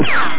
home *** CD-ROM | disk | FTP | other *** search / Horror Sensation / HORROR.iso / sounds / iff / rico2.snd ( .mp3 ) < prev next > Amiga 8-bit Sampled Voice | 1992-09-02 | 3KB | 1 channel | 8,135 sample rate | 0.06 seconds